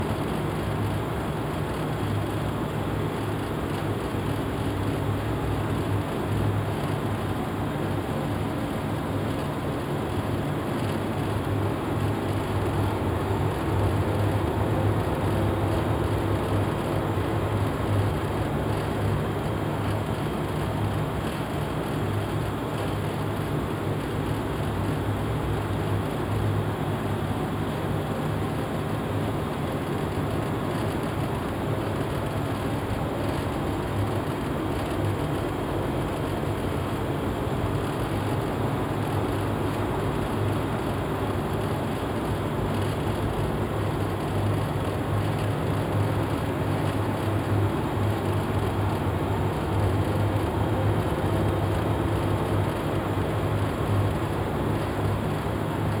Machine Room Loop 3.wav